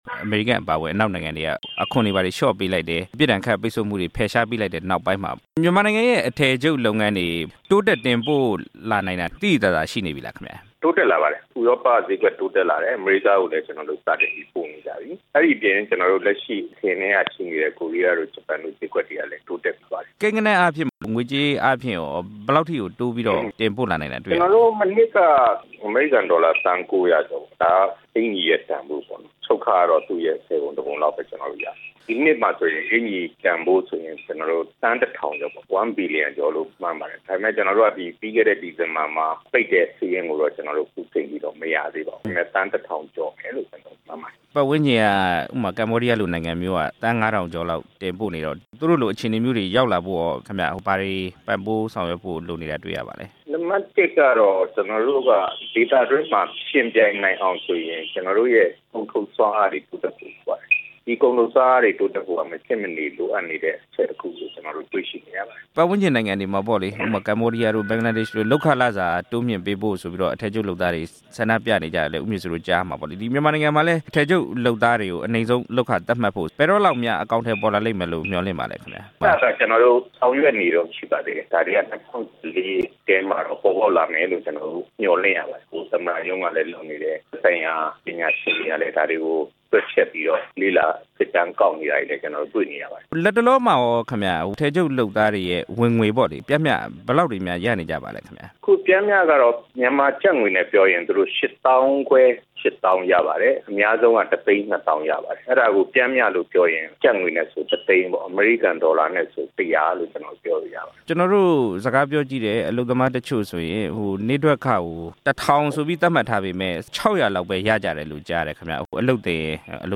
မြန်မာနိုင်ငံ အထည်ချုပ်လုပ်ငန်း အခြေအနေ မေးမြန်းချက်